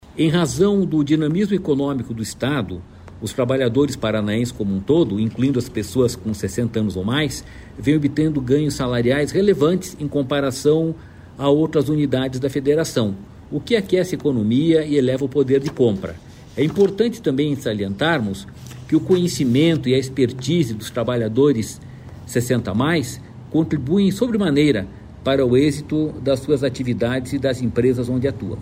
Sonora do diretor-presidente do Ipardes, Jorge Callado, sobre o crescimento da renda média das pessoas com 60 anos ou mais empregadas no Paraná